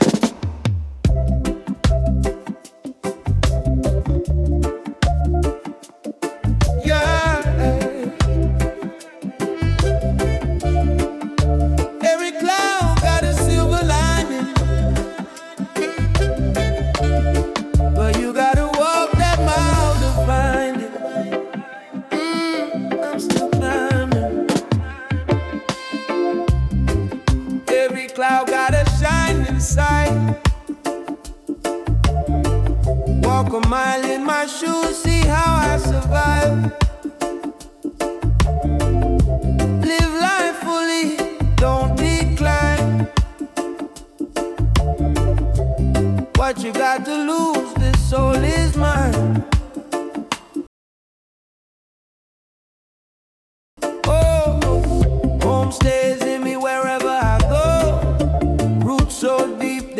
🎧 Genre: Reggae ⏱ Length